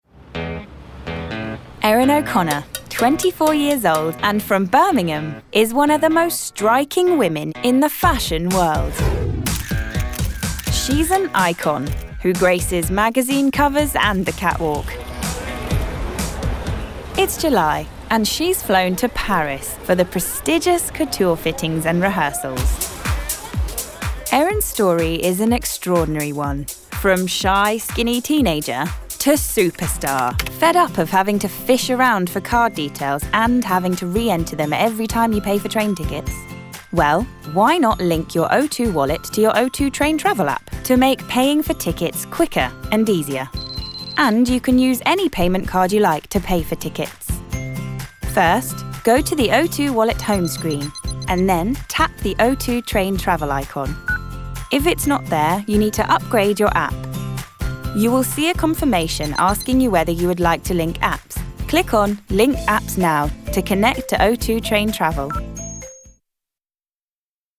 Gender Female
Accent Essex Estuary English Irish Irish Southern Liverpool London Southern American Standard English R P Standard U S
Narration & Documentary Clips